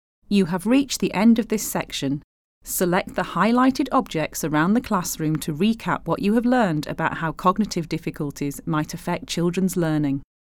Narration Listen to Narration Narration audio (MP3) Narration audio (OGG)